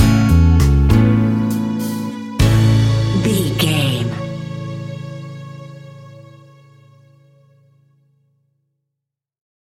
An exotic and colorful piece of Espanic and Latin music.
Ionian/Major
Slow
romantic
maracas
percussion spanish guitar